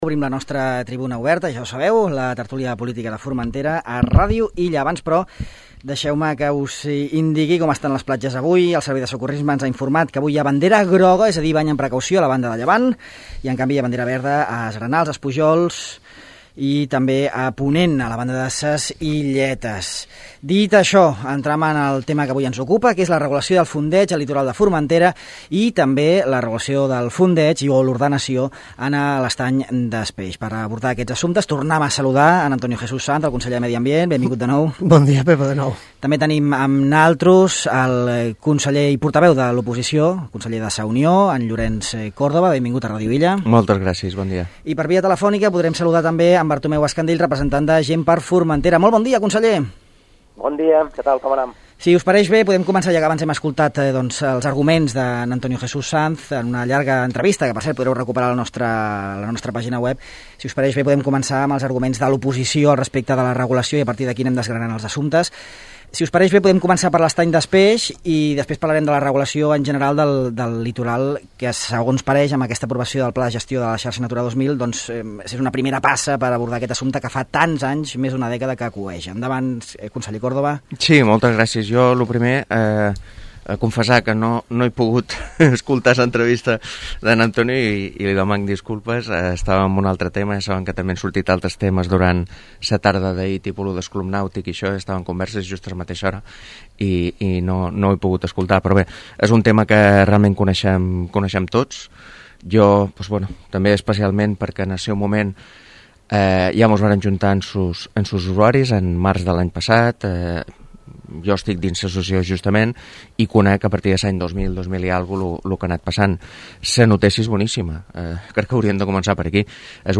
Tertúlia política sobre l’ordenació de fondejos a l’estany des Peix
La futura ordenació dels fondejos a l’estany des Peix protagonitza la tribuna política d’aquest dijous amb la participació d’Antonio J Sanz (PSOE), Llorenç Córdoba (Sa Unió) i Bartomeu Escandell (GxF). Els representants de les tres formacions amb representació al Consell Insular debaten sobre la idoneïtat del projecte analitzant-ne els pros i contres i ressaltant la importància d’una ordenació que obtingui el màxim consens, tot respectant la pertinença de l’estany al parc natural de ses Salines de Formentera i Eivissa.